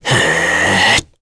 Esker-Vox_Casting3.wav